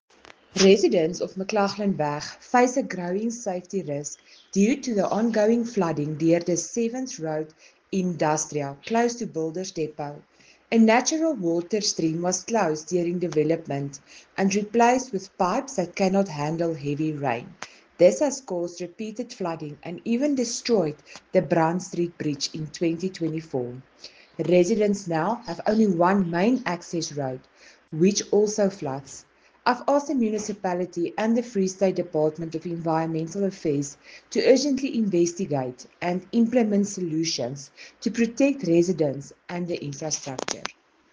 Afrikaans soundbites by Cllr Marelize Boeije and